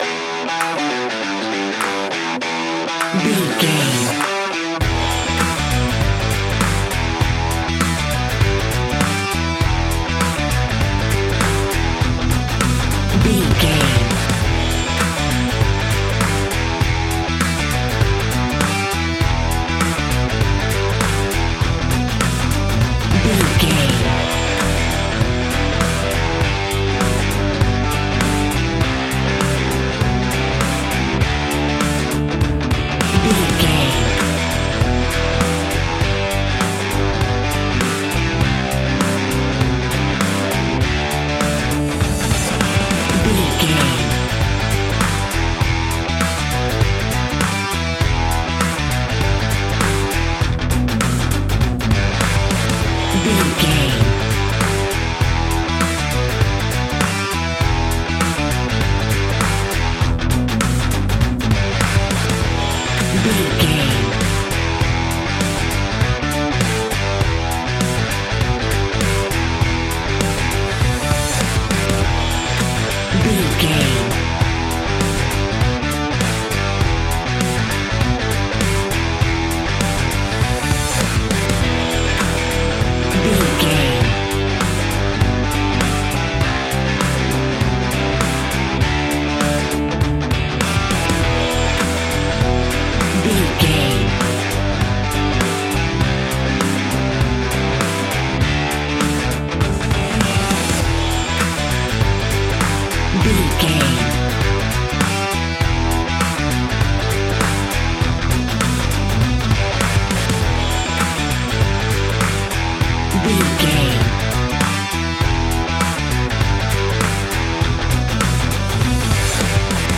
Fast paced
Aeolian/Minor
energetic
electric guitar
bass guitar
drums